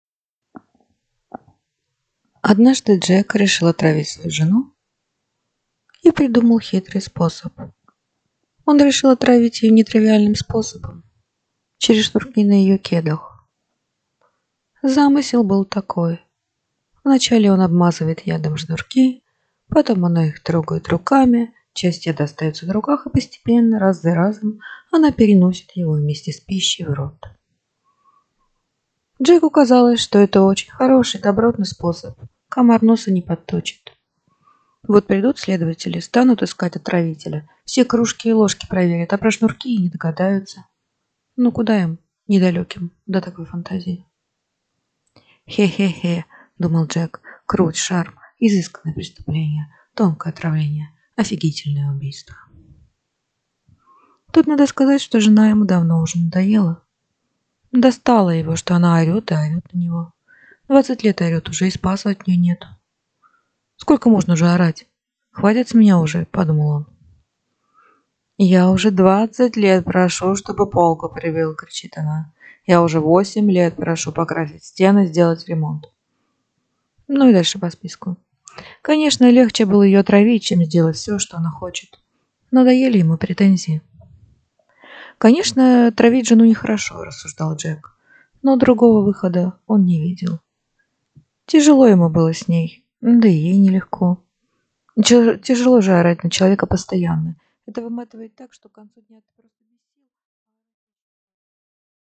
Аудиокнига Дело об отравленных шнурках | Библиотека аудиокниг